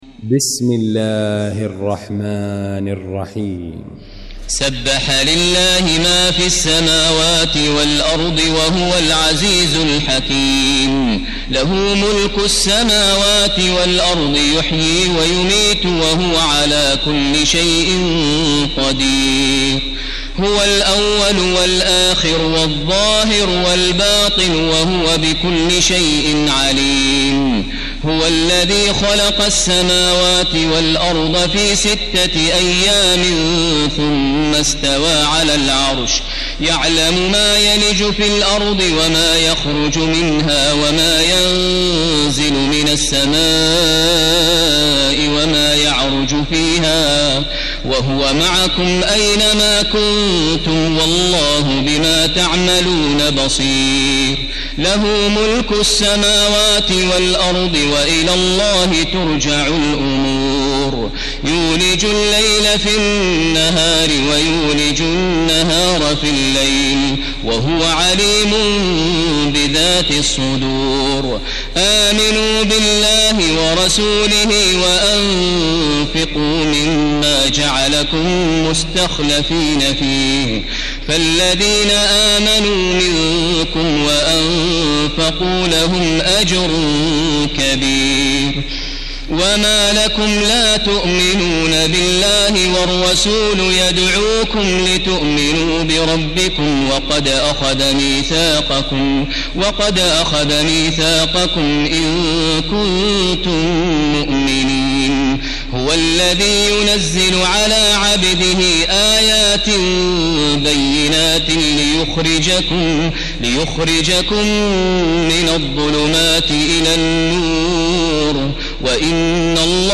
المكان: المسجد الحرام الشيخ: فضيلة الشيخ ماهر المعيقلي فضيلة الشيخ ماهر المعيقلي الحديد